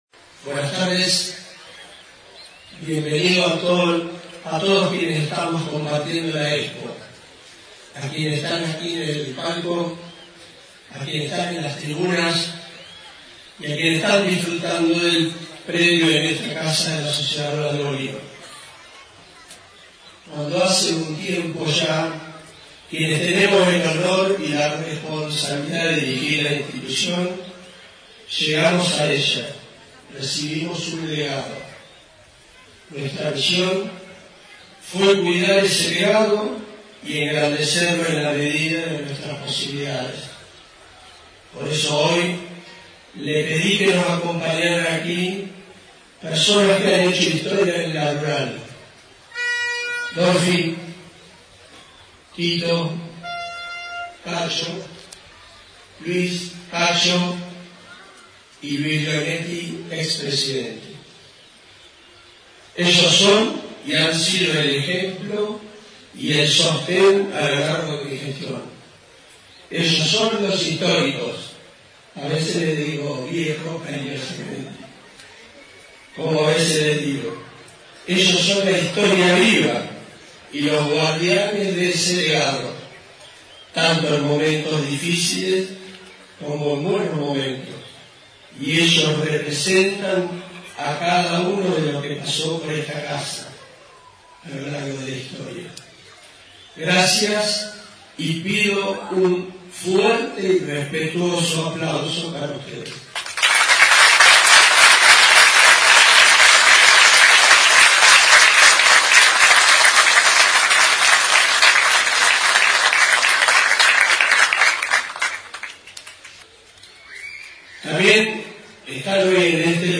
Inauguración Exposición Rural de Bolívar :: Radio Federal Bolívar